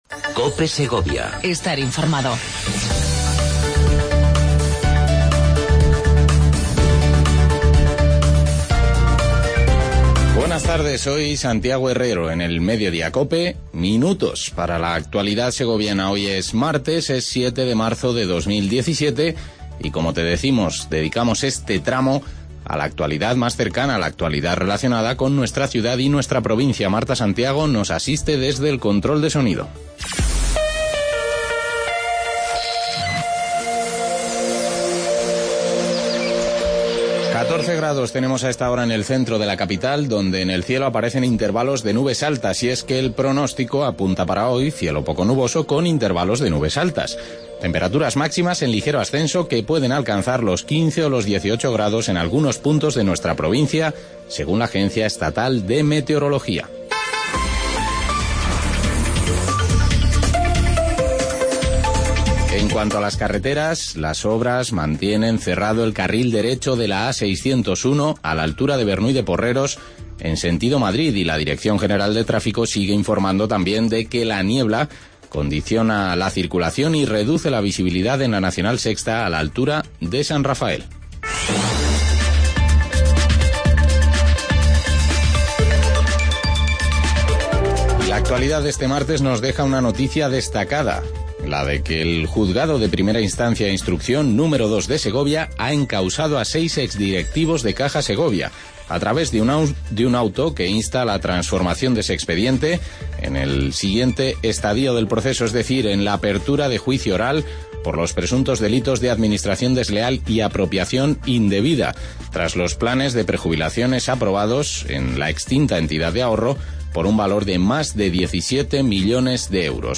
Redacción digital Madrid - Publicado el 07 mar 2017, 14:06 - Actualizado 18 mar 2023, 23:38 1 min lectura Descargar Facebook Twitter Whatsapp Telegram Enviar por email Copiar enlace Avance de las noticias más destacadas del día.